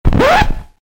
Record Scratch